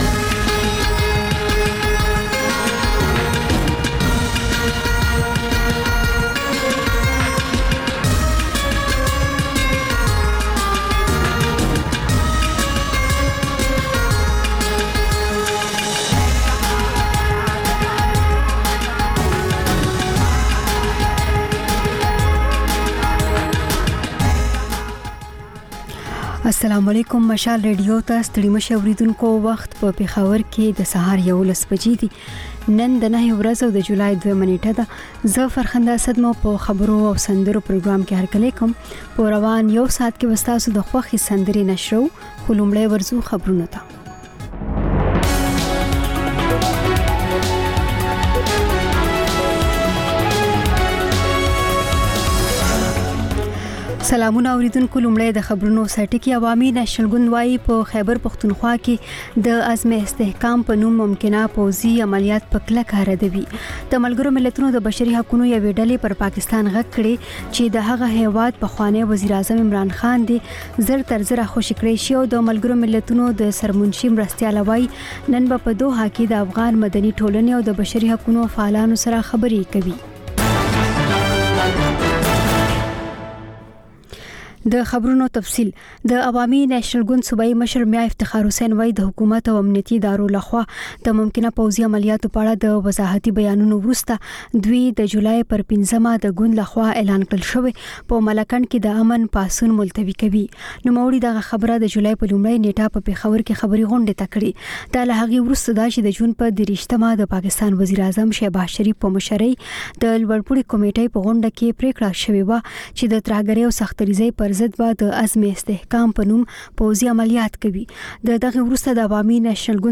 په دې خپرونه کې تر خبرونو وروسته له اورېدونکو سره په ژوندۍ بڼه خبرې کېږي، د هغوی پیغامونه خپرېږي او د هغوی د سندرو فرمایشونه پوره کول کېږي.